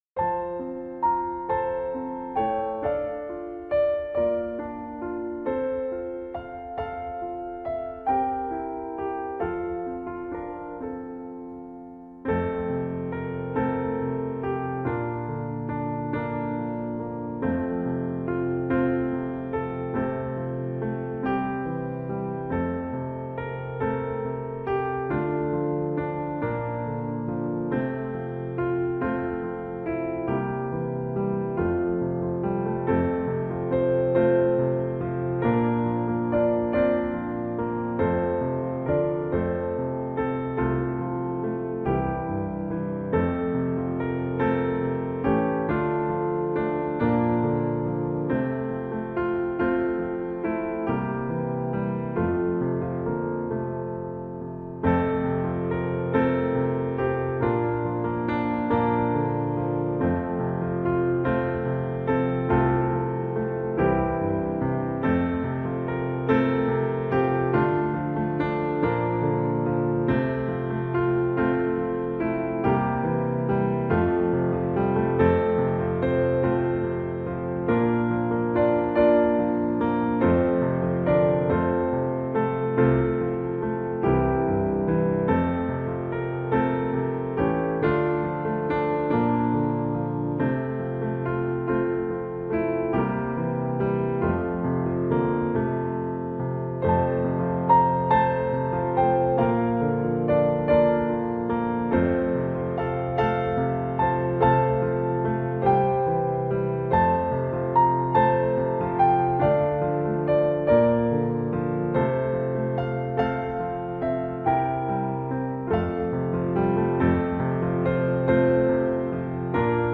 piano instrumental hymn